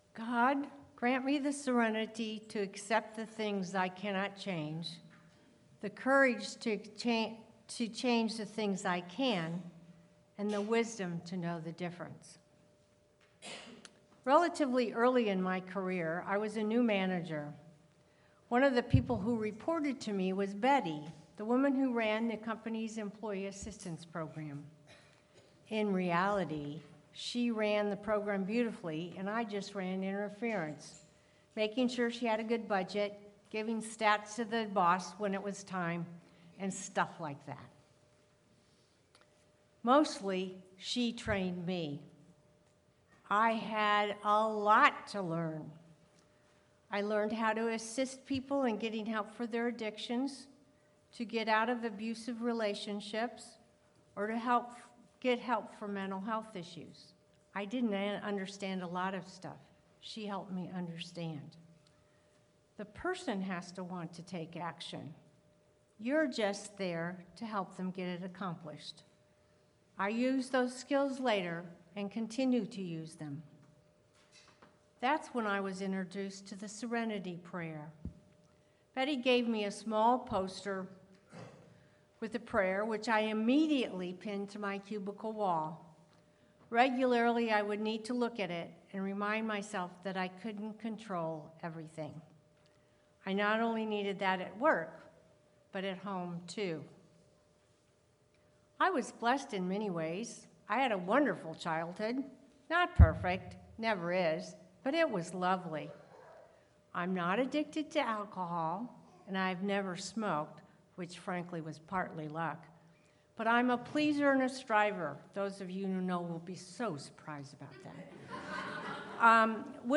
Sermon-The-Serenity-Prayer.mp3